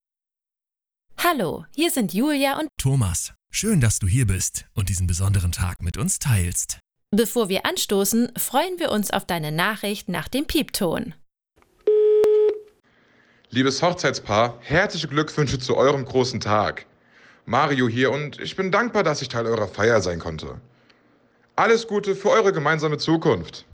Durch den Einsatz hochwertiger Komponenten garantieren wir eine klare und störfreie Aufnahme der eingesprochenen Botschaften.
Audiogästebuch mieten - Sprachbeispiele:
Hochzeit:
Beispiel_Hochzeitl.wav